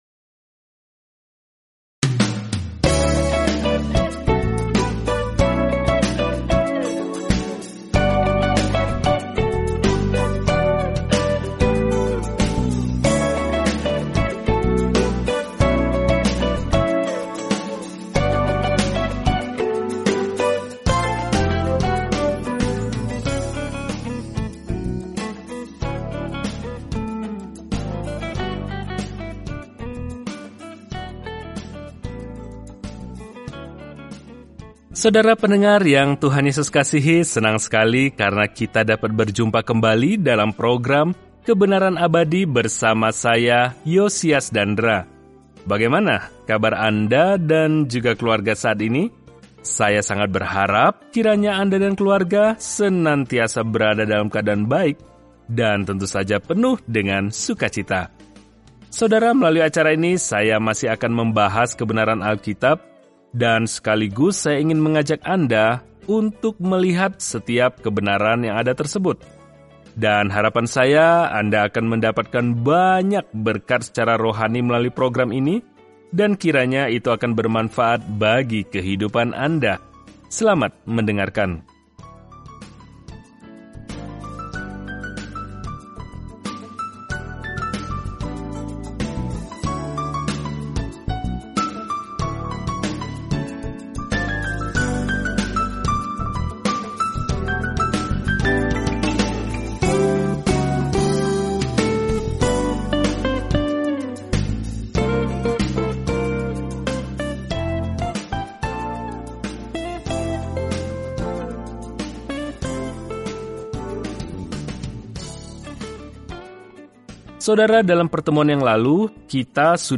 Firman Tuhan, Alkitab Wahyu 1:5-9 Hari 4 Mulai Rencana ini Hari 6 Tentang Rencana ini Wahyu mencatat akhir dari garis waktu sejarah dengan gambaran bagaimana kejahatan pada akhirnya akan dibasmi dan Tuhan Yesus Kristus akan memerintah dengan segala otoritas, kuasa, keindahan, dan kemuliaan. Perjalanan sehari-hari melalui Wahyu saat Anda mendengarkan studi audio dan membaca ayat-ayat tertentu dari firman Tuhan.